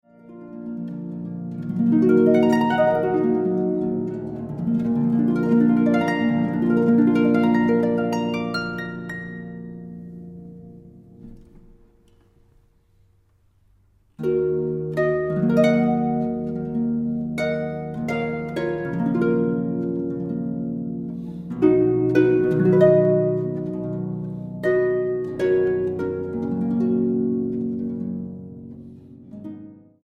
arpa.